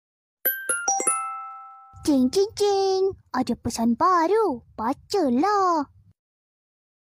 Genre: Nada dering imut